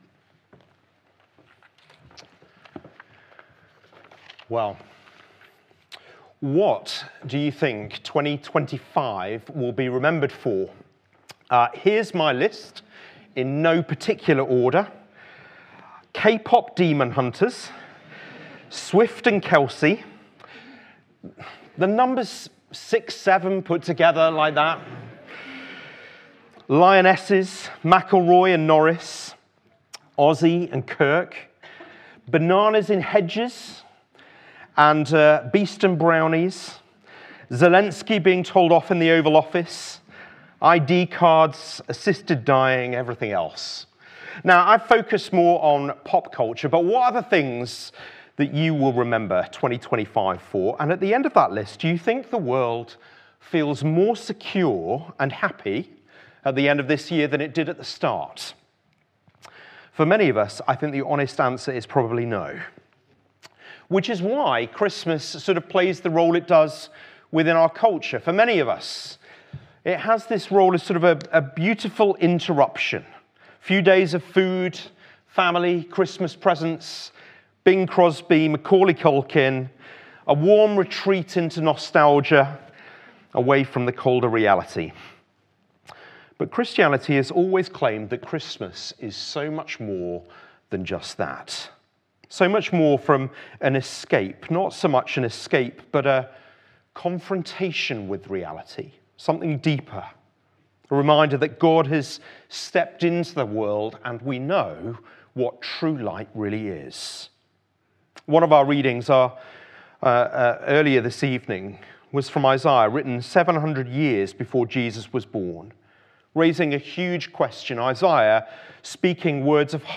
Carol Service 21 December